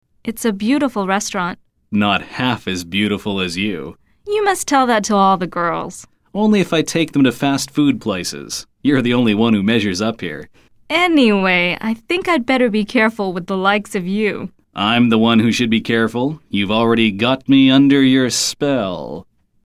聽力大考驗：來聽老美怎麼說？